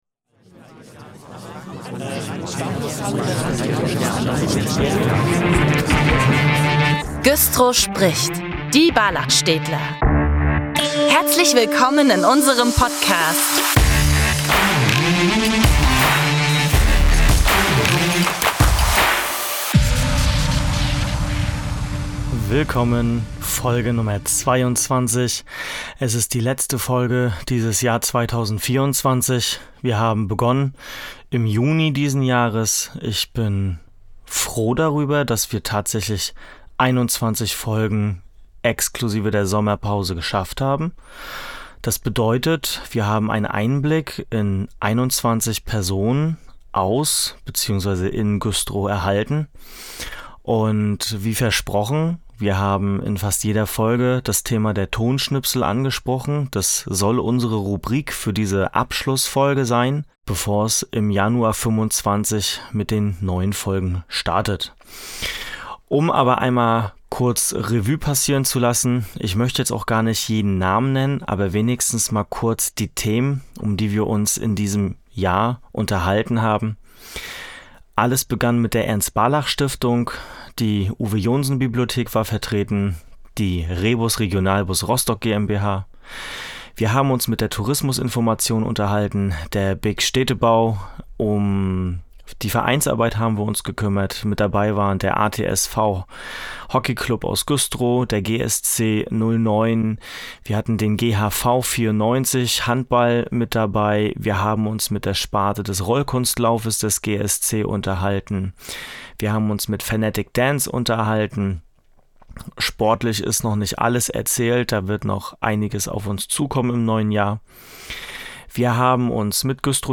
1 022 - Der Podcast im Jahr 2024 - eine Zusammenfassung mit Tonschnipseln 11:24
tonschnipsel_2024(1).mp3